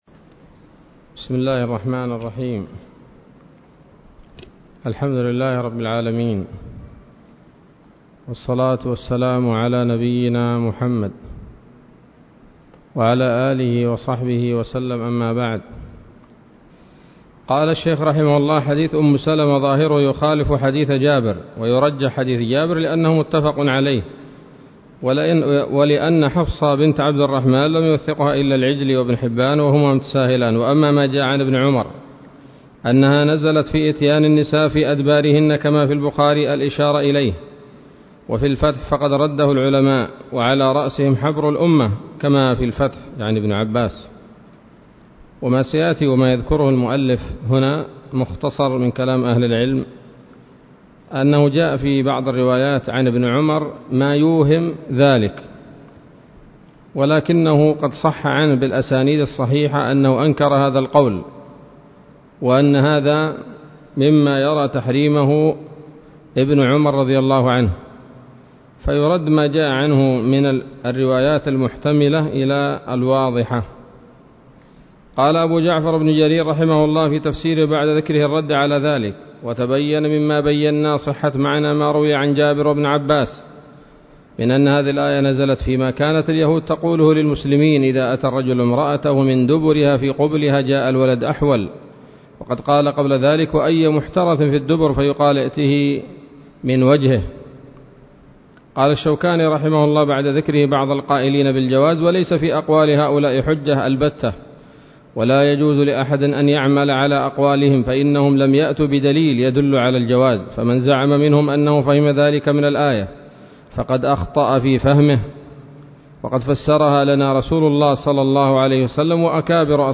الدرس الرابع عشر من الصحيح المسند من أسباب النزول